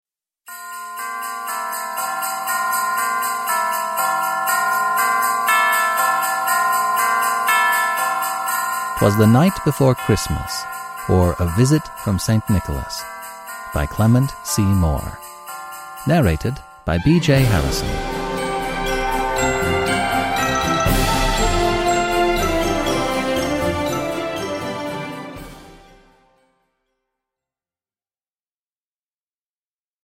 Produkttyp: Digitala böcker
He masterfully plays with a wide array of voices and accents and has since then produced over 500 audiobooks.